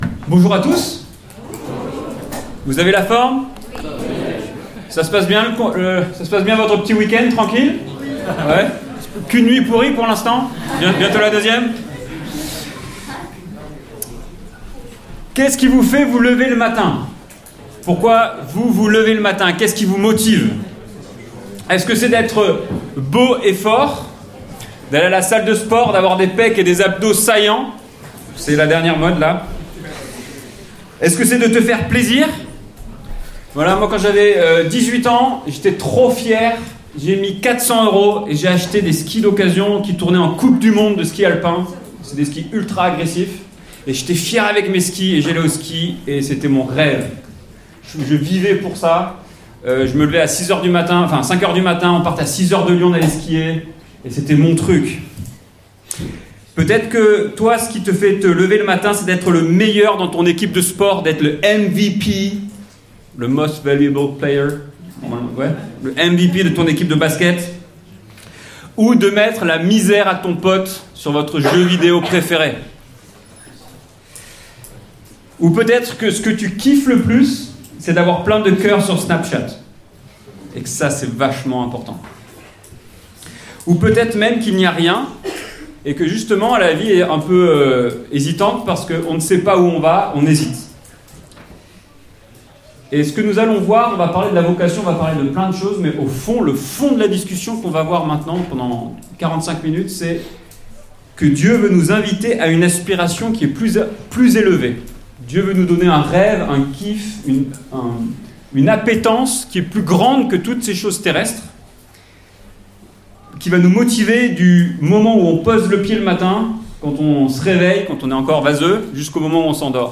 Ateliers Pâques 2025, Vivant